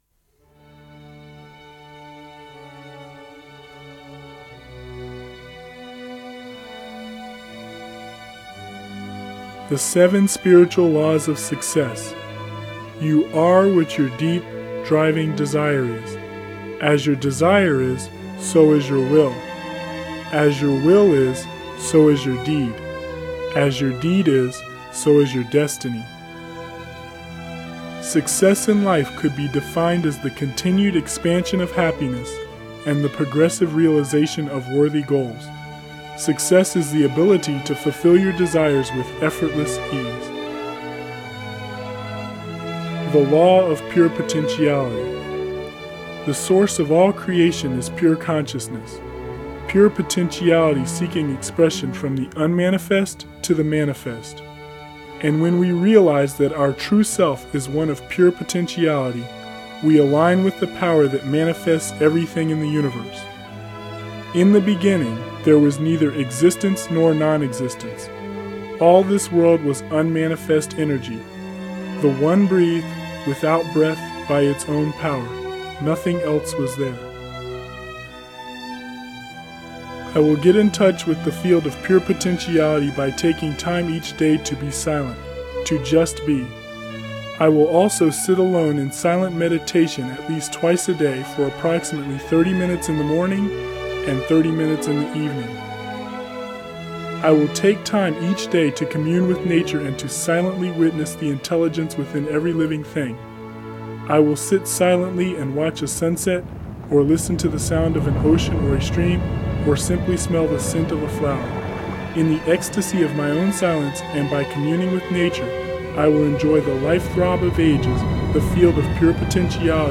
Affirmations Audiobook